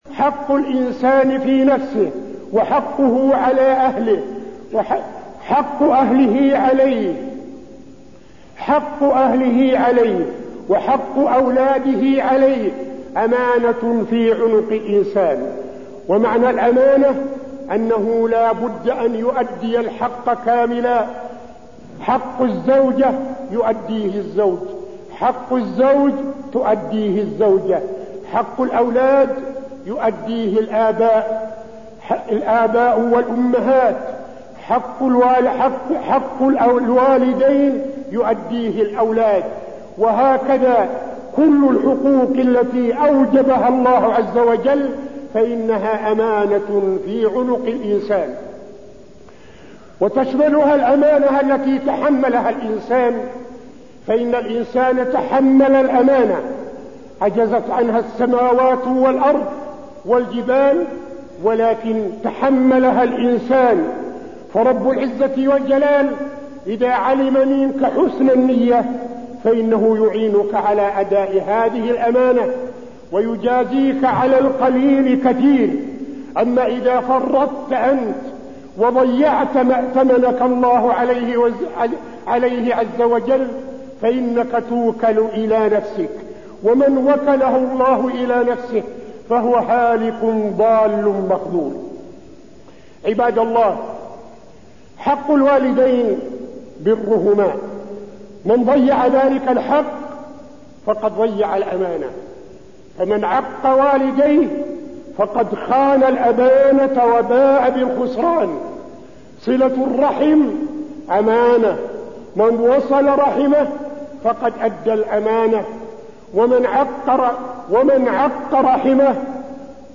تاريخ النشر ٢٥ جمادى الأولى ١٤٠٥ هـ المكان: المسجد النبوي الشيخ: فضيلة الشيخ عبدالعزيز بن صالح فضيلة الشيخ عبدالعزيز بن صالح الأمانة في كل الأعمال